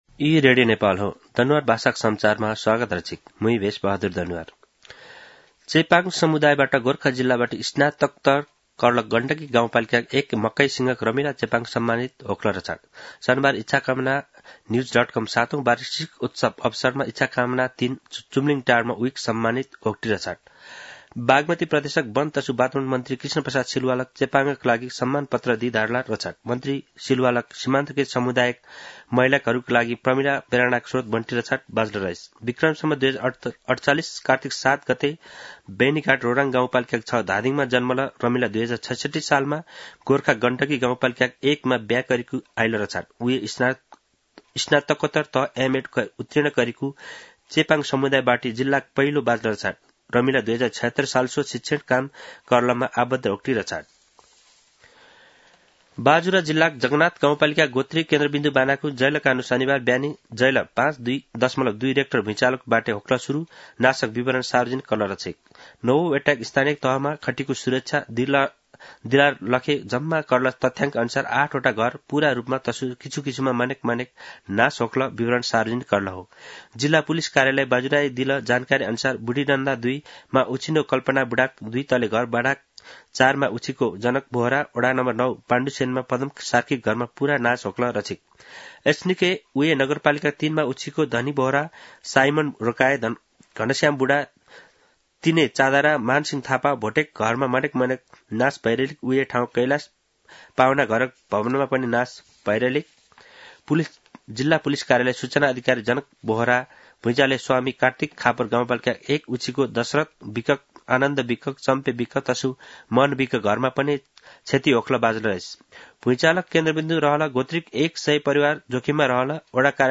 दनुवार भाषामा समाचार : ८ पुष , २०८१